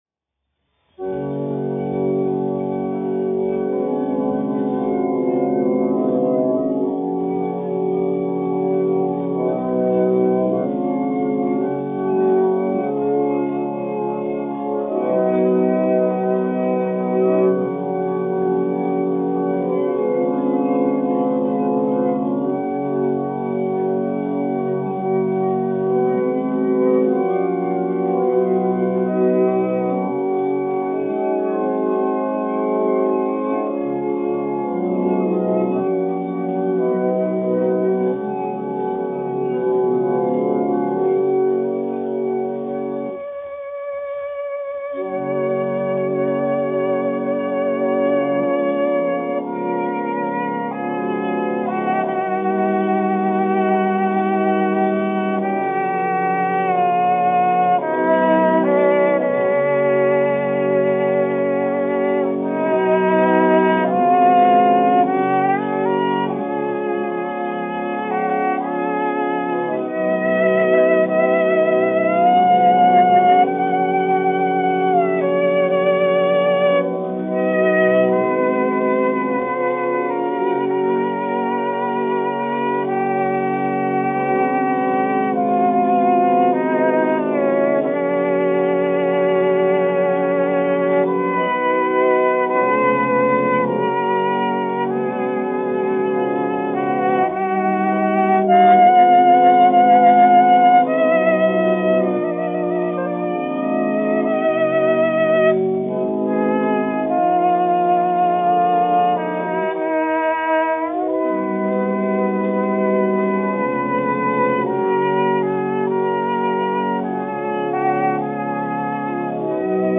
1 skpl. : analogs, 78 apgr/min, mono ; 25 cm
Operas--Fragmenti, aranžēti
Latvijas vēsturiskie šellaka skaņuplašu ieraksti (Kolekcija)